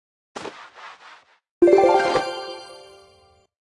Media:Sfx_Anim_Ultimate_Archer Queen.wav 动作音效 anim 在广场点击初级、经典、高手、顶尖和终极形态或者查看其技能时触发动作的音效
Sfx_Anim_Baby_Archer_Queen.wav